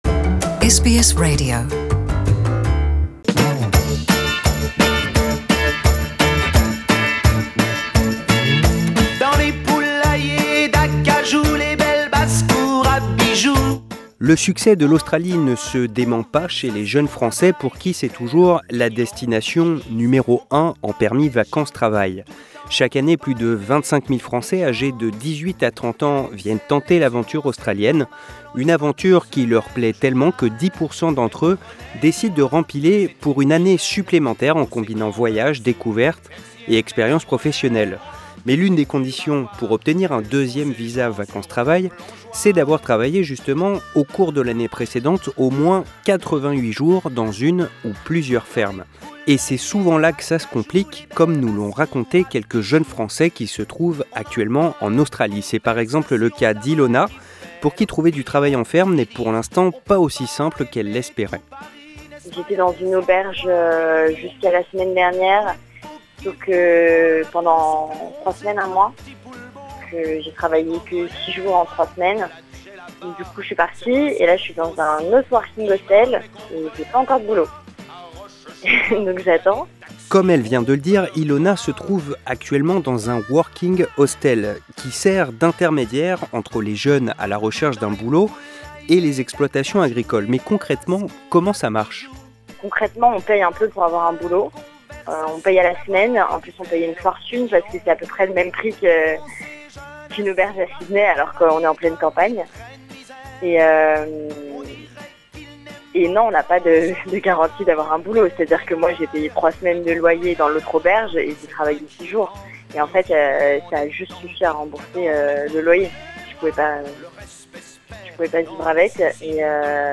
Quatre jeunes Français nous racontent leur expérience du travail dans des fermes australiennes, condition indispensable pour obtenir un deuxième visa vacances-travail.